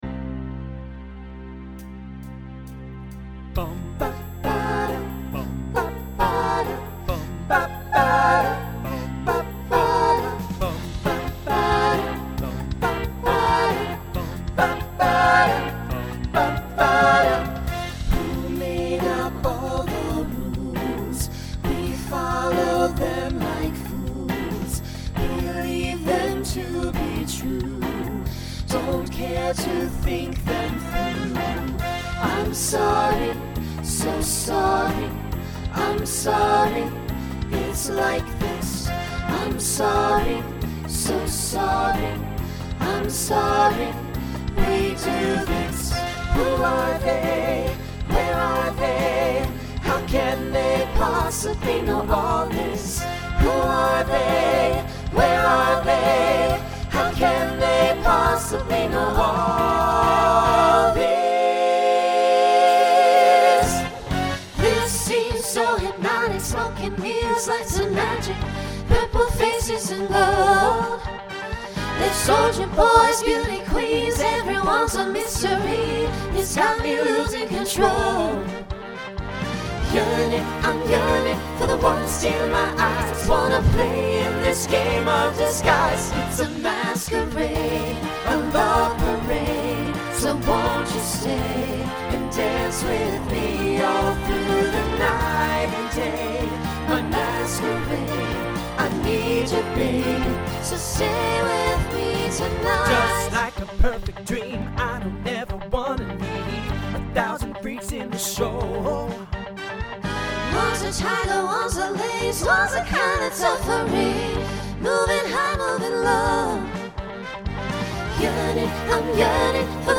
Genre Pop/Dance Instrumental combo
Voicing SATB